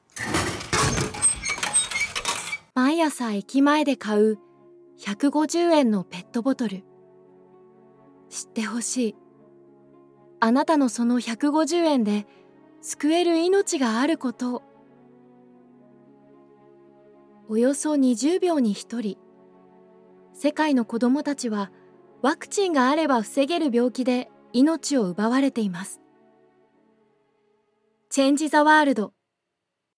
Sanft
Natürlich